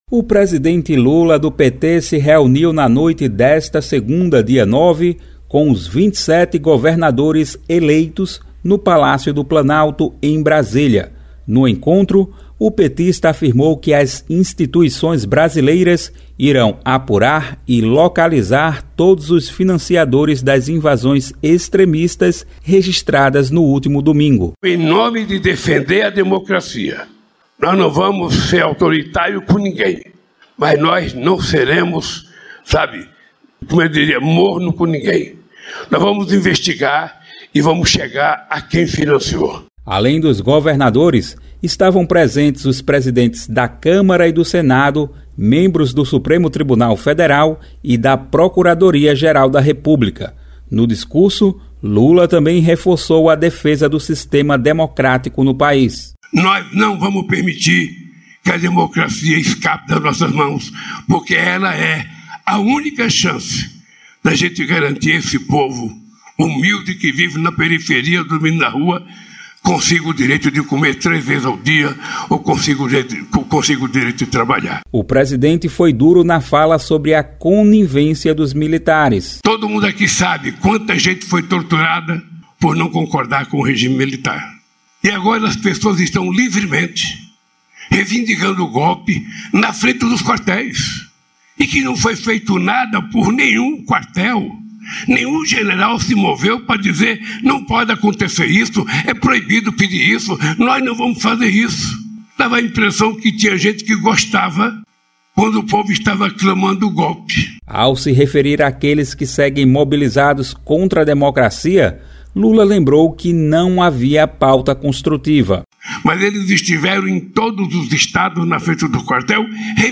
Em reunião com os 27 governadores, presidentes da Câmara e do Senado, membros do Supremo Tribunal Federal (STF) e Procuradoria-Geral da República (PGR) na noite nesta segunda-feira (9), no Palácio do Planalto, em Brasília (DF), o presidente Lula (PT) disse que as instituições brasileiras irão apurar e localizar todos os financiadores das invasões extremistas registradas no domingo.